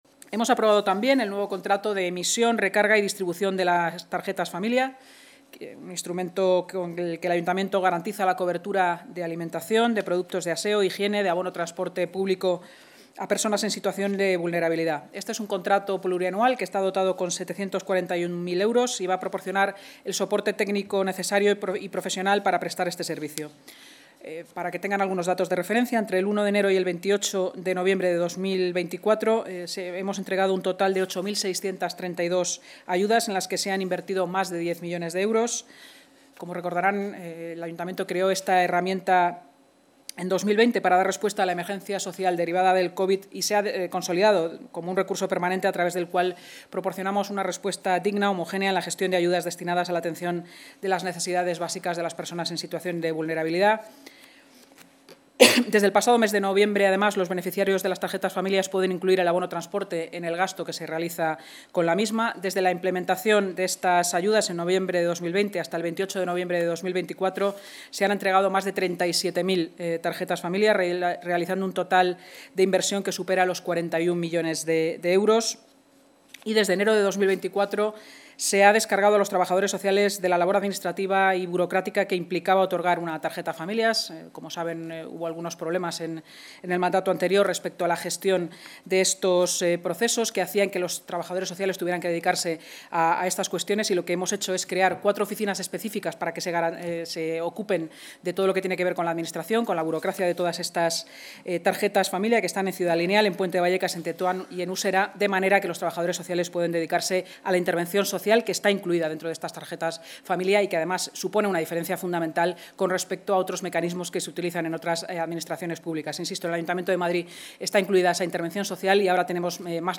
Nueva ventana:Inma Sanz, portavoz municipal